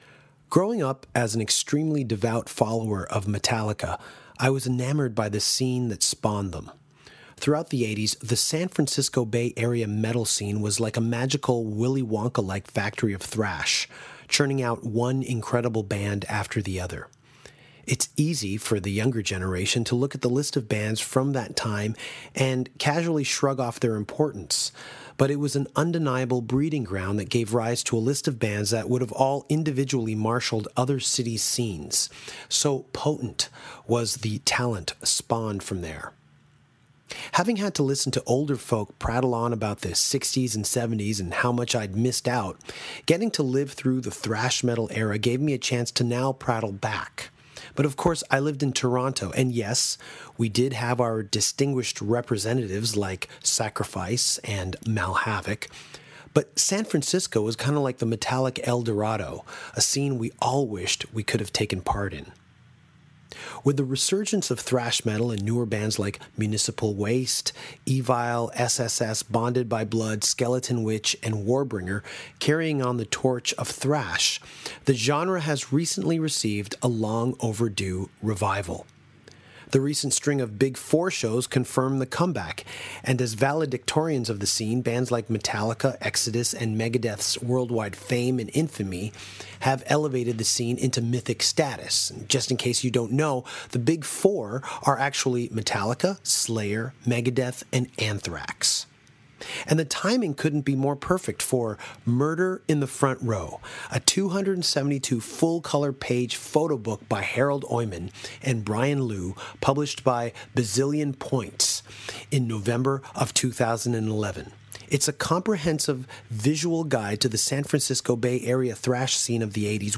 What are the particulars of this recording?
while on tour via Skype